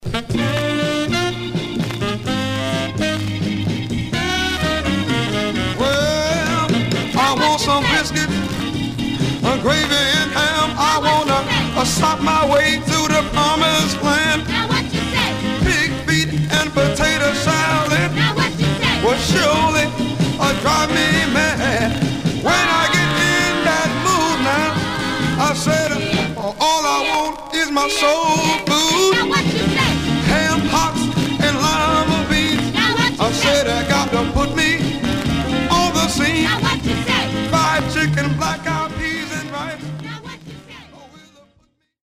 Stereo/mono Mono
Funk